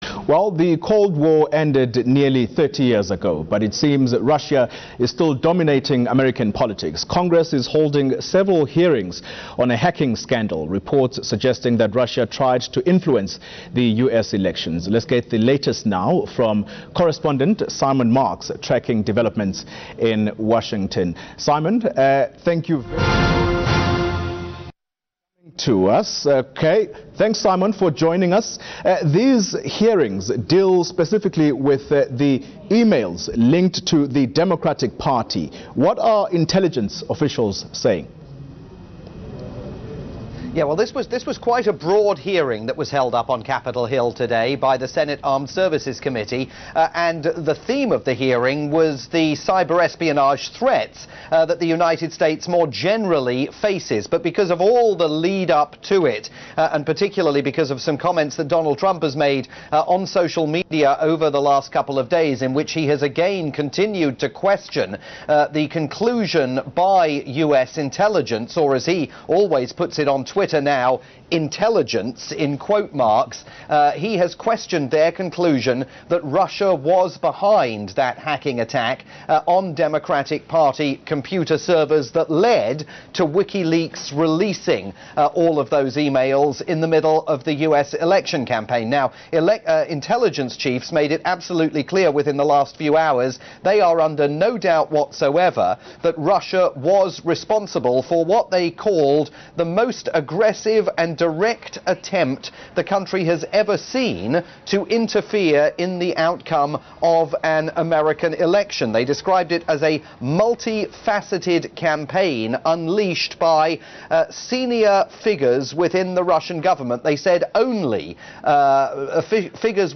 report on the Senate Armed Services Committee hearings involving top US intelligence officials, via South Africa's top-rated news channel ENCA.